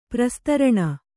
♪ prastaraṇa